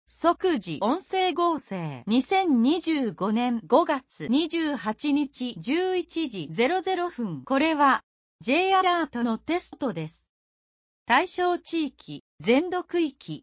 「即時音声合成」 2025年05月28日11時00分 これは、Jアラートのテストです。